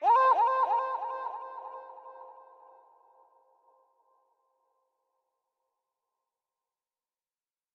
TS - CHANT (7).wav